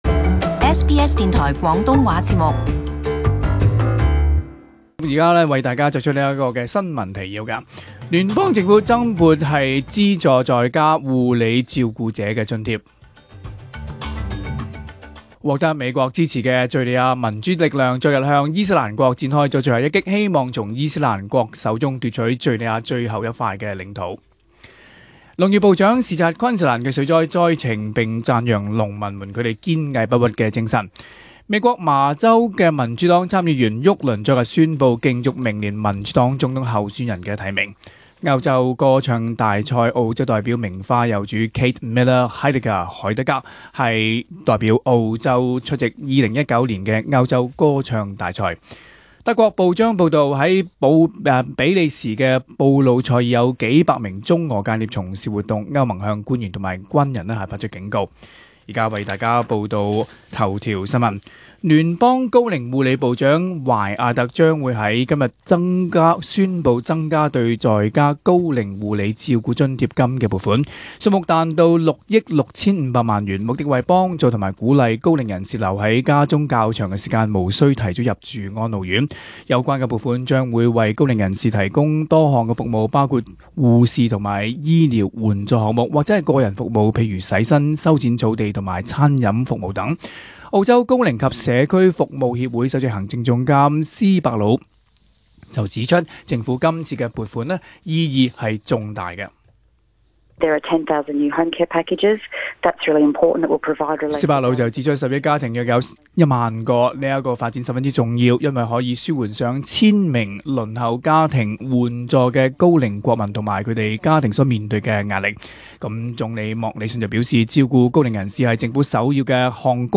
SBS Chinese News 10.2.2019 Detailed morning news bulletin